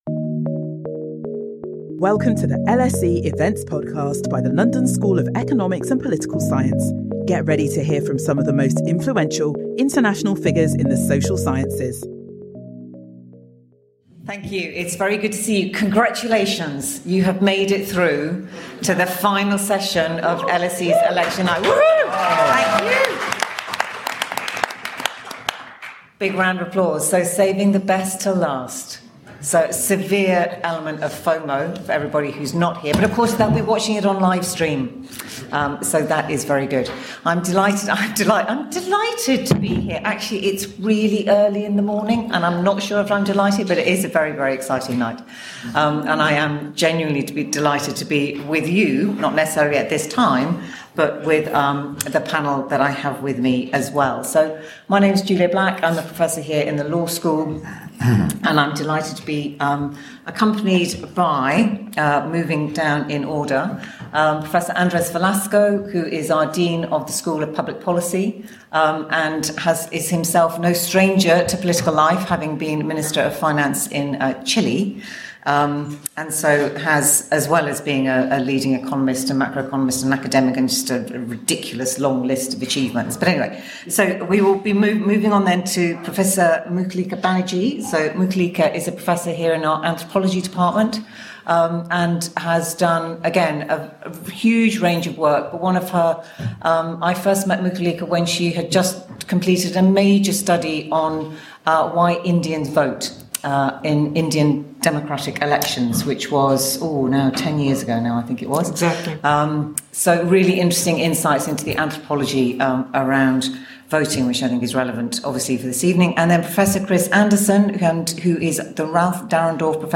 Our panel discuss what this election tells us about the health of Britain’s democracy in context of the elections taking place around the world this year.